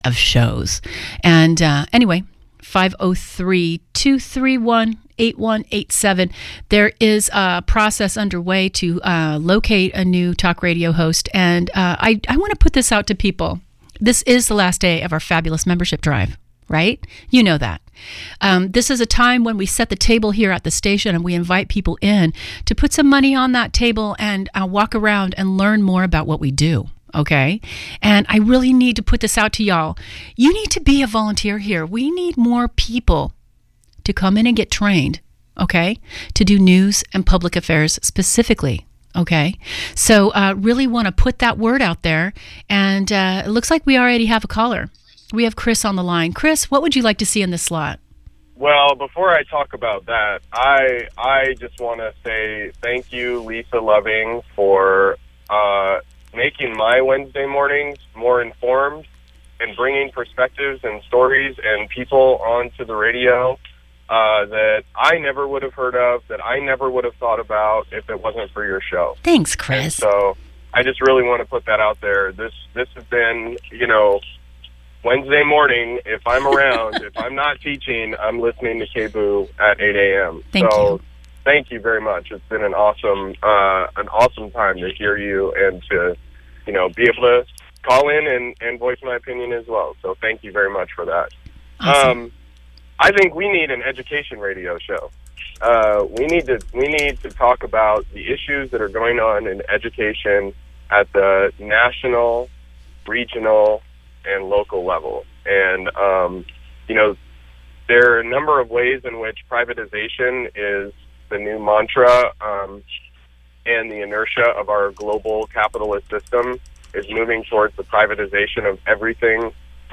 Wednesday Talk Radio